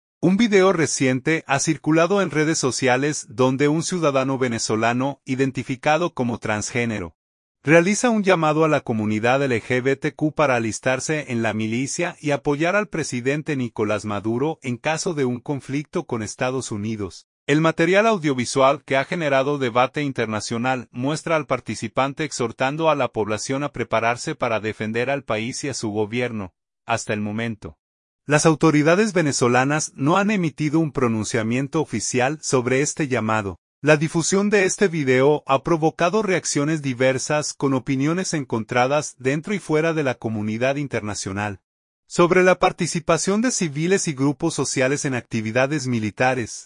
El material audiovisual, que ha generado debate internacional, muestra al participante exhortando a la población a prepararse para defender al país y a su gobierno.